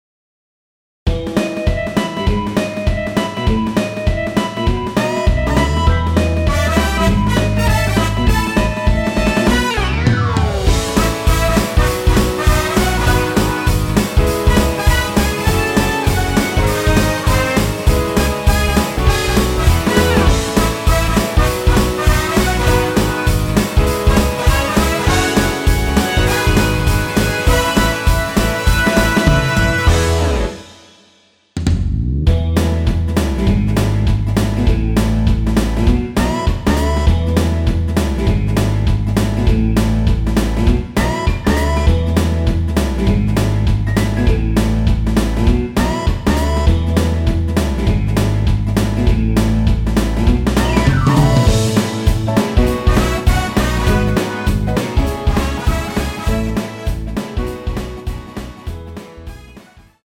노래방에서 음정올림 내림 누른 숫자와 같습니다.
앞부분30초, 뒷부분30초씩 편집해서 올려 드리고 있습니다.
중간에 음이 끈어지고 다시 나오는 이유는